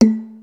35. 35. Percussive FX 34 ZG